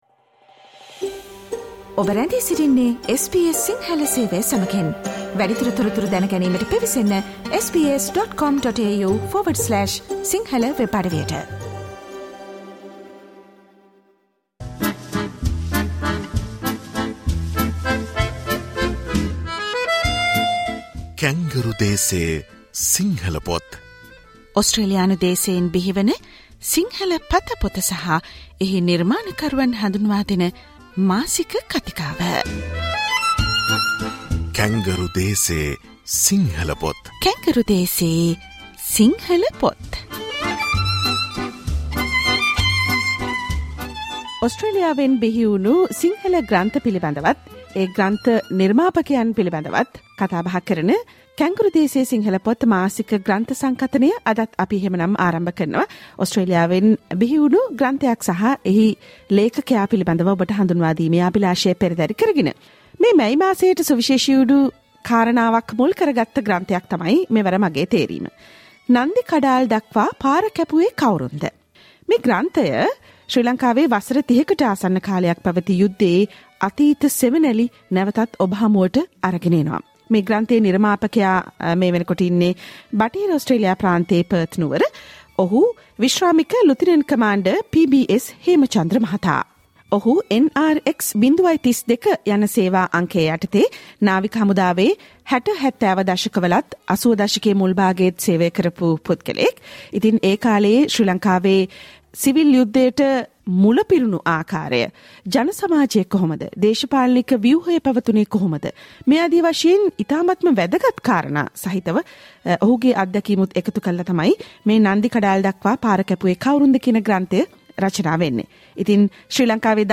Listen to the SBS Sinhala radio discussion